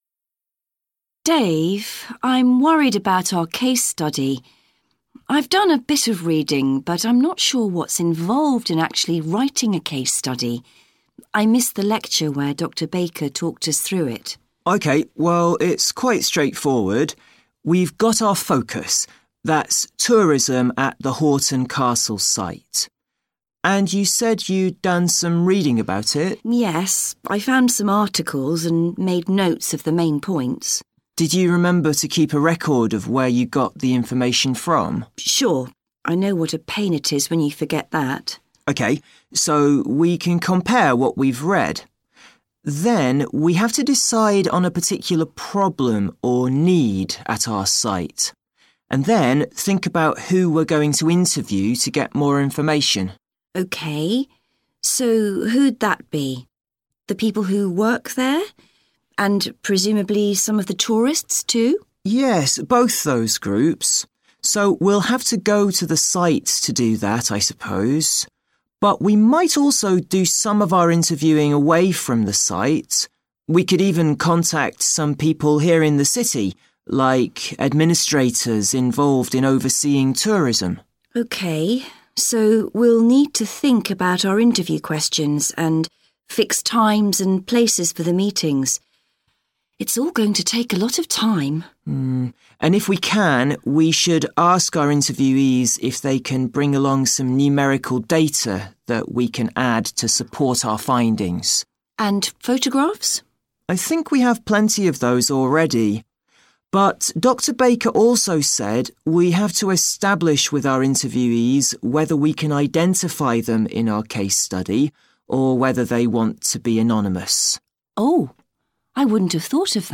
Two students are discussing a case study they have to write.